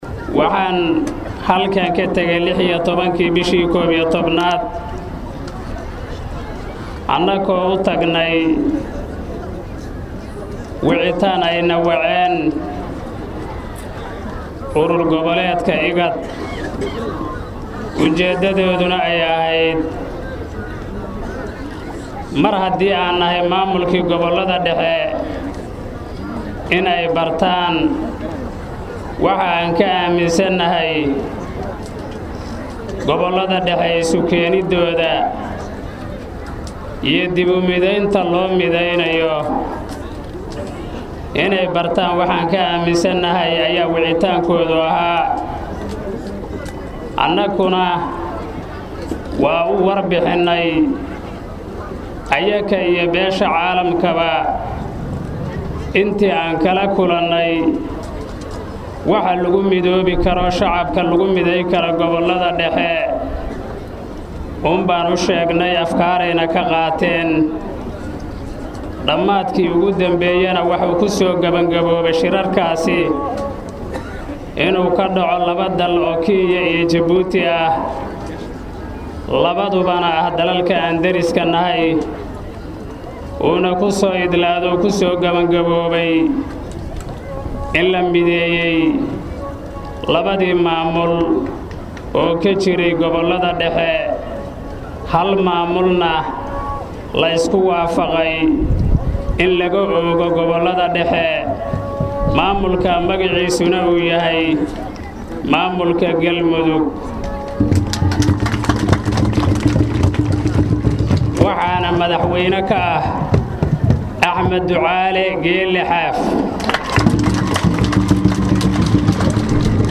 Shiikh Maxamed Shaakir Cali Xasan Madaxa Xukumadda Galmudug oo Warbaahinta Qaranka kula hadlay Magaalada Dhuusamareeb ayaa sheegay in ay u